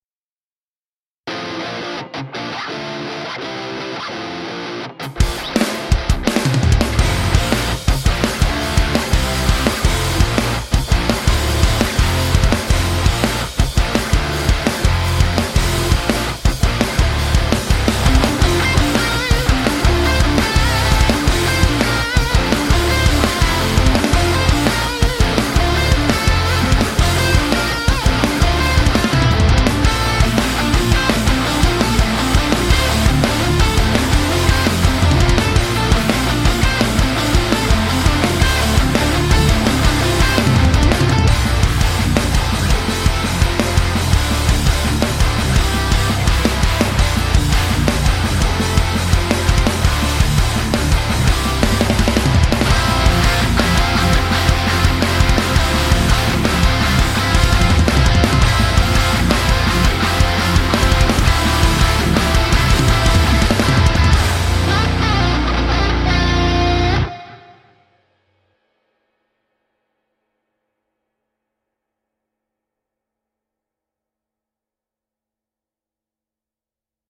- 它只包含一套完美调音的鼓组，包括一个大鼓、一个小鼓、两个军鼓、一个镲铜和五个钹。
- 它的鼓声富有活力和个性，适合制作高能量的流行朋克音乐，也可以用于其他现代风格的音乐。